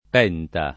[ p $ nta ]